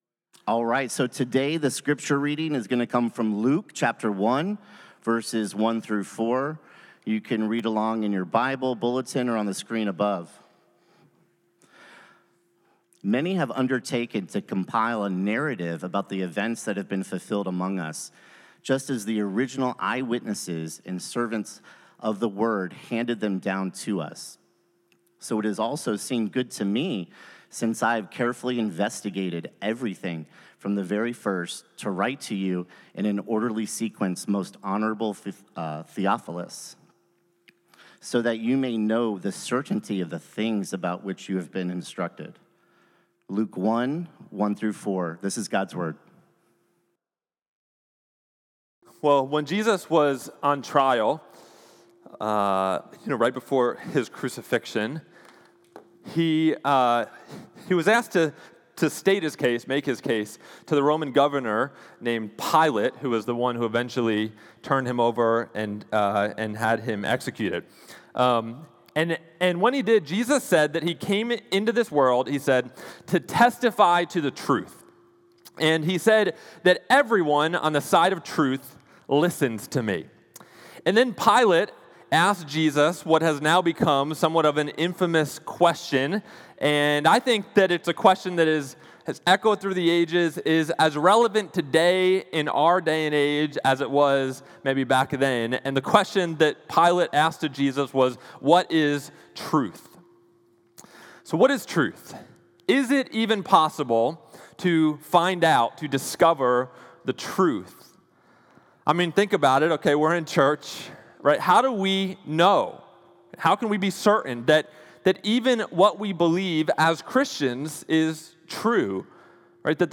2025-11-30-Sermon.m4a